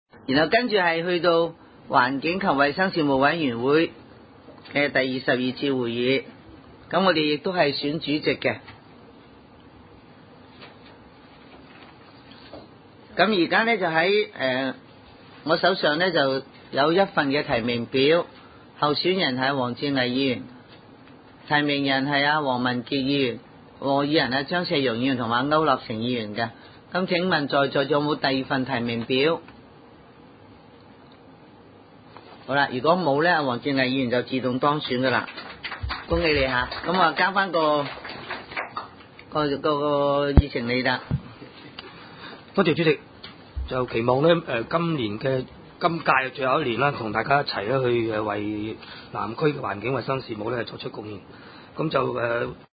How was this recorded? Venue: SDC Conference Room